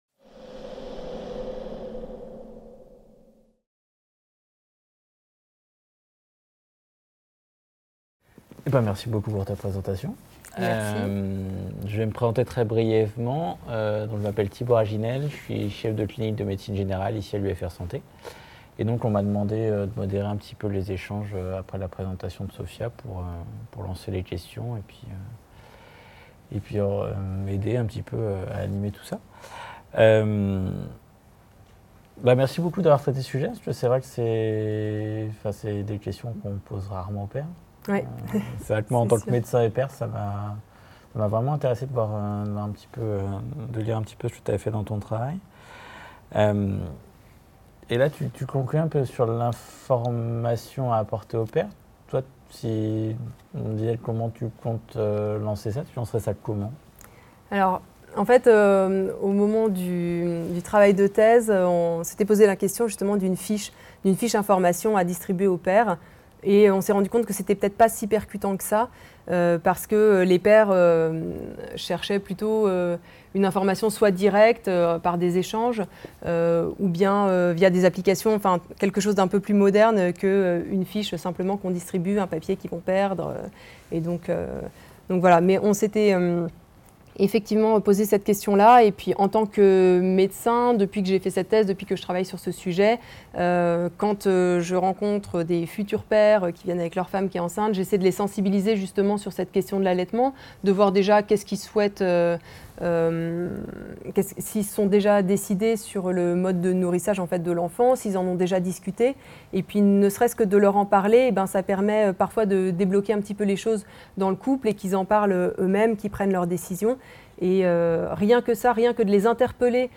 1B - Rôles des pères dans l'initiation et le soutien à l'allaitement maternel d’après leurs propres expériences - Table ronde (tep2018) | Canal U
Les conférences santé de la BU